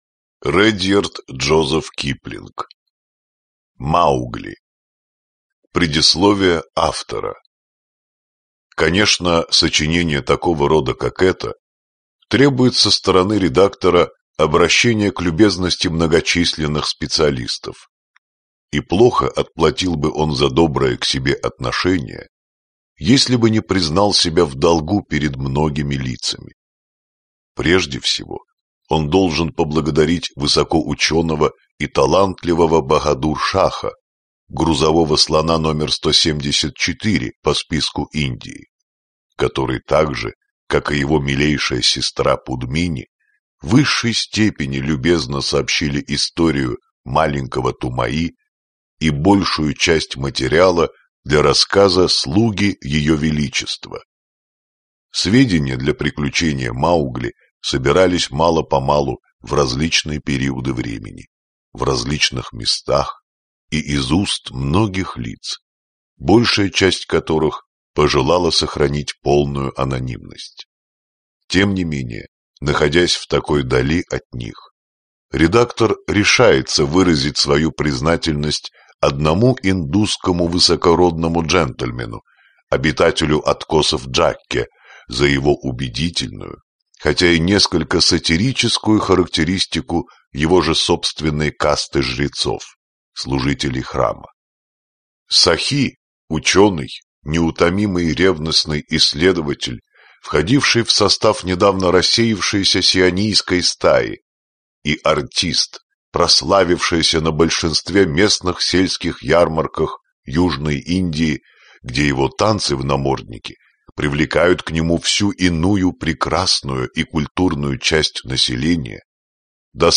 Аудиокнига Маугли. Книги джунглей 1, 2 | Библиотека аудиокниг
Прослушать и бесплатно скачать фрагмент аудиокниги